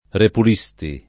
repul&Sti] (pop. ripulisti [ripul&Sti]) s. m. — interpretaz. scherz. della frase del salmo 43 quare me repulisti?, «perché mi respingesti?», come se la voce verbale appartenesse all’it. ripulire anziché al lat. repellere; quindi la locuz. fare un r., «far piazza pulita»